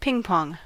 Ääntäminen
Ääntäminen Tuntematon aksentti: IPA: /tɪʃtɛnɪs/ Haettu sana löytyi näillä lähdekielillä: saksa Käännös Ääninäyte Substantiivit 1. table tennis 2. ping pong US Artikkeli: das .